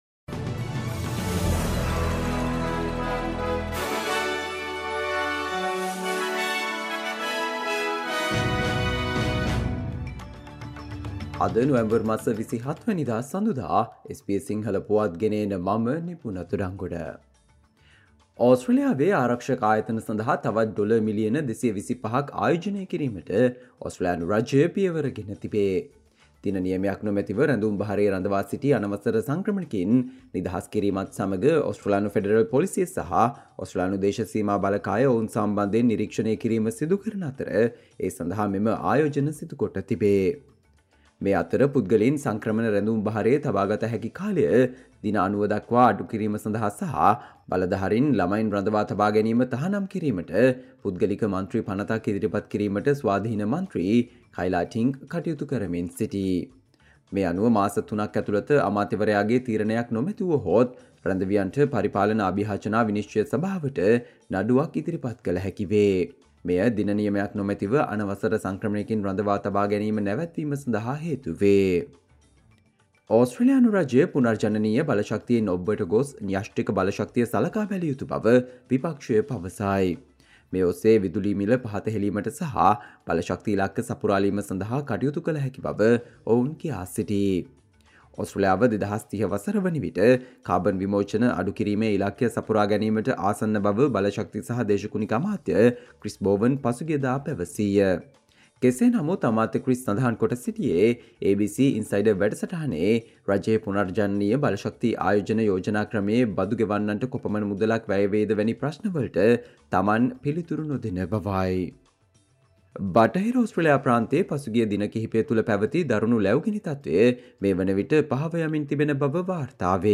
Australia news in Sinhala, foreign and sports news in brief - listen, Monday 20 November 2023 SBS Sinhala Radio News Flash